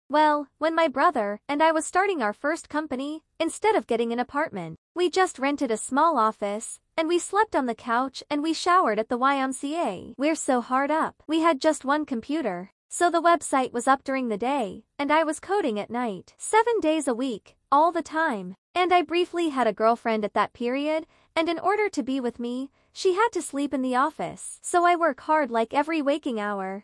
girl.mp3